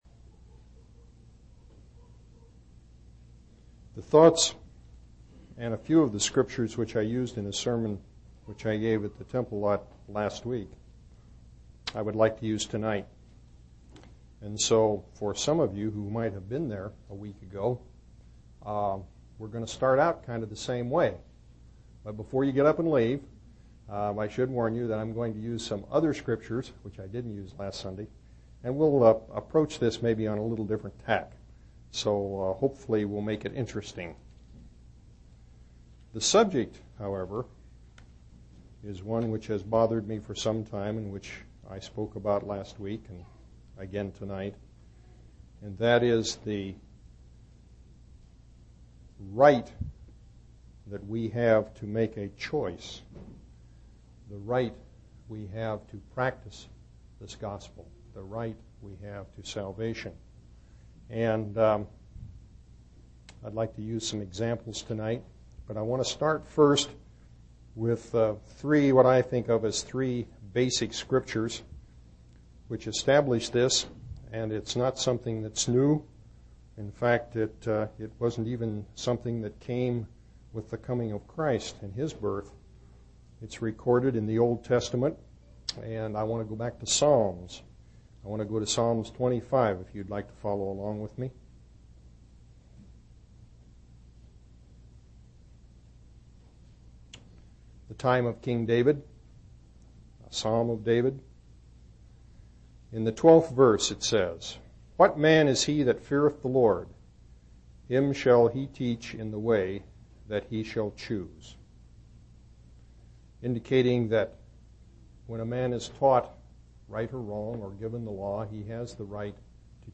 7/7/1991 Location: Temple Lot Local Event